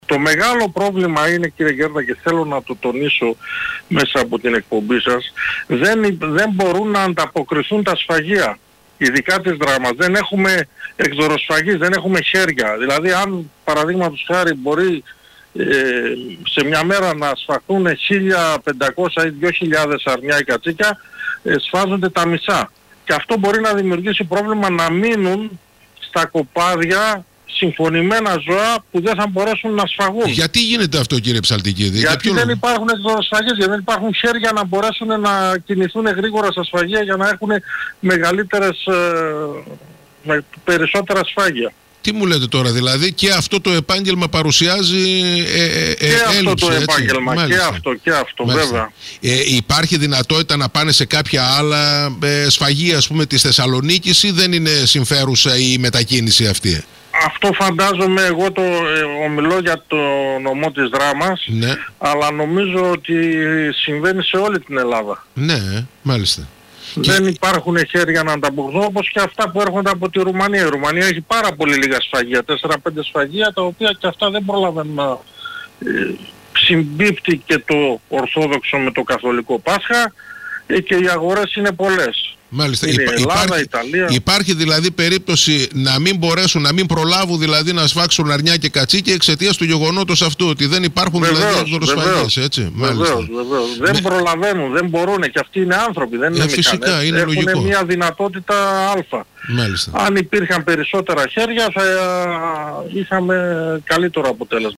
σε συνέντευξη του στον ALPHA NEWS 95,5.